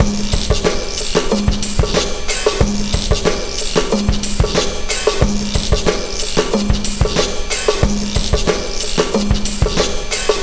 Memphis Belle 092bpm